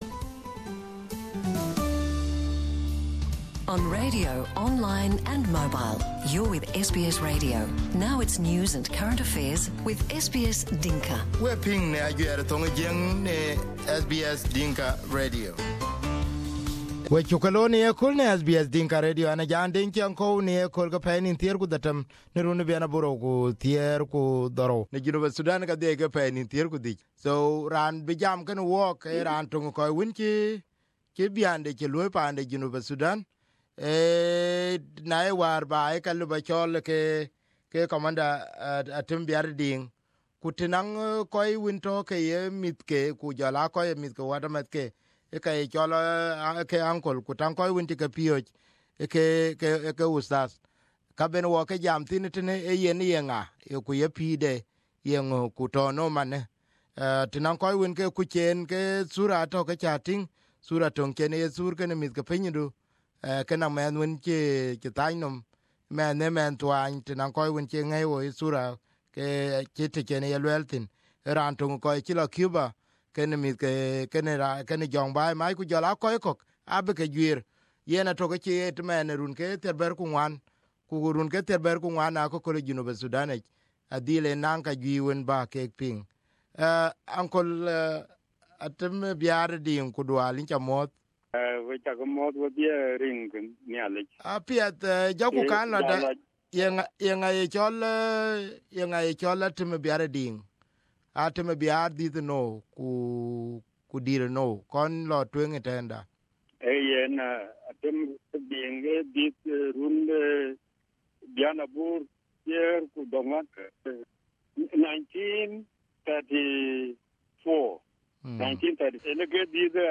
Here is the first interview on SBS Dinka Radio.